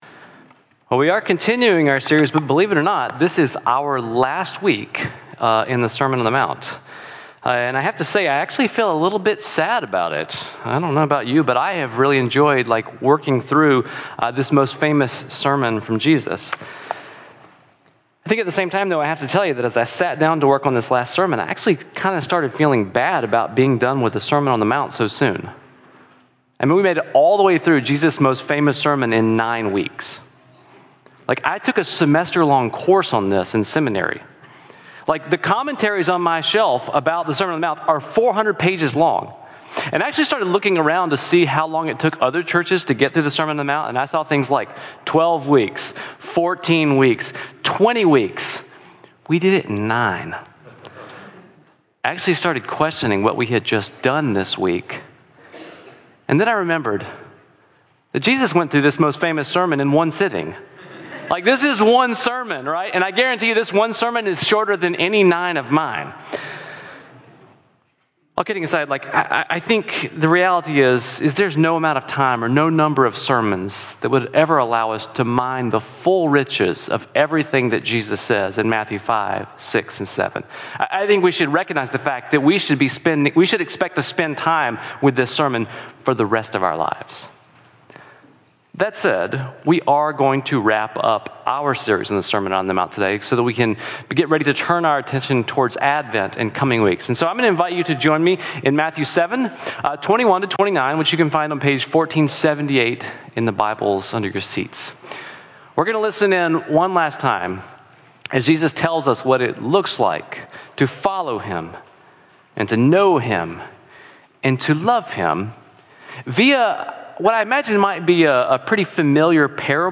For the final sermon, we will focus on Matthew 7:21-29, which underscores Jesus’ sober warning about true and false Christians—those who genuinely follow and commune with Him versus those who only claim to know Him. Learn how to build a life grounded in a genuine relationship with God, beyond merely performing religious actions.